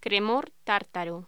Locución: Cremor tártaro
voz